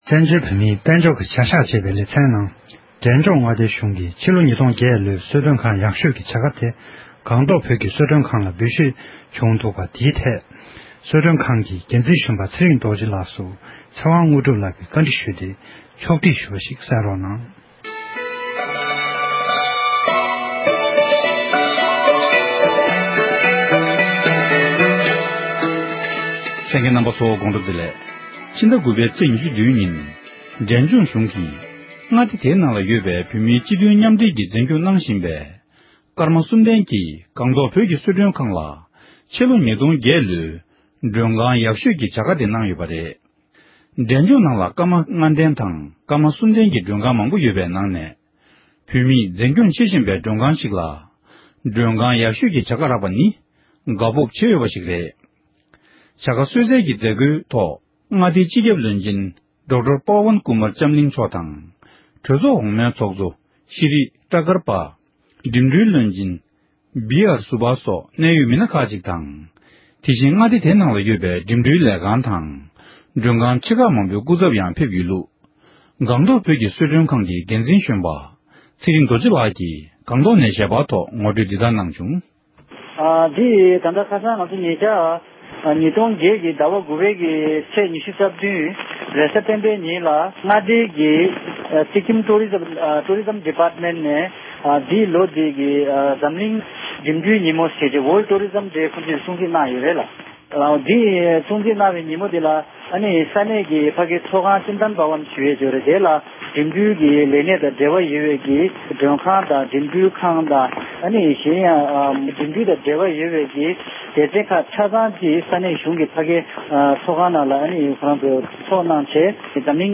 འདྲི་ཞུས